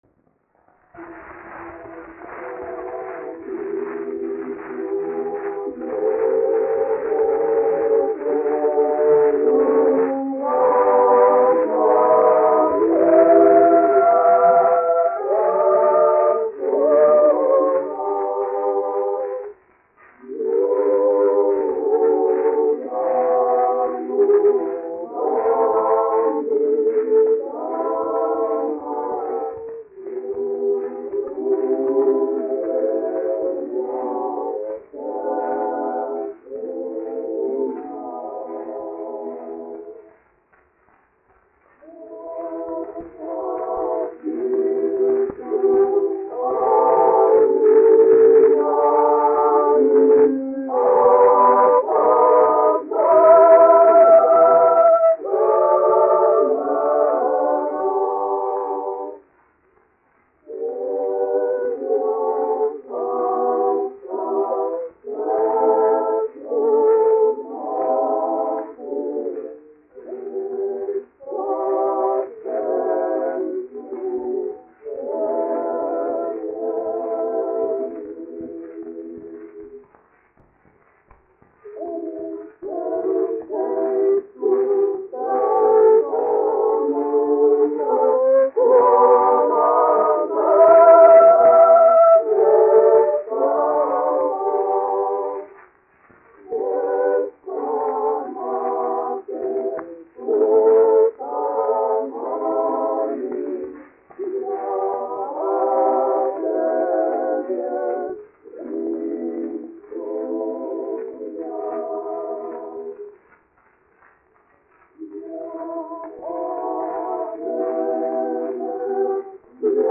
1 skpl. : analogs, 78 apgr/min, mono ; 25 cm
Latviešu tautasdziesmas
Kori (jauktie)
Skaņuplate
Latvijas vēsturiskie šellaka skaņuplašu ieraksti (Kolekcija)